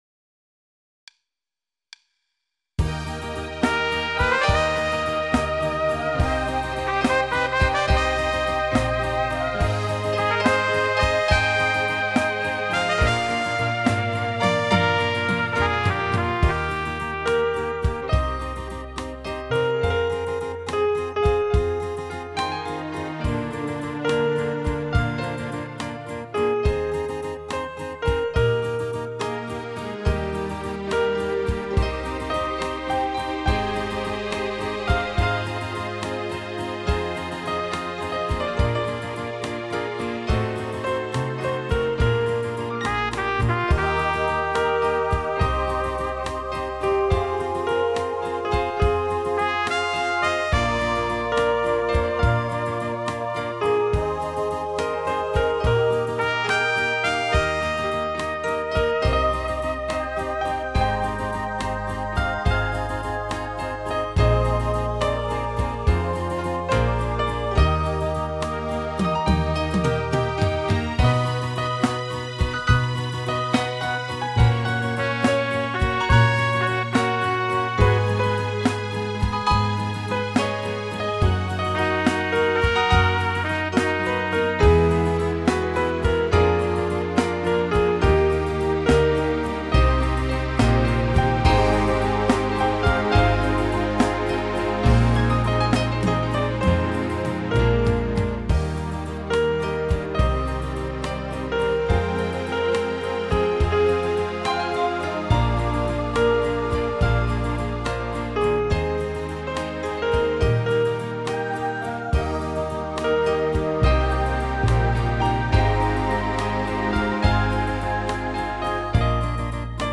6/  Krásne české skladby
upravené pre hru na piano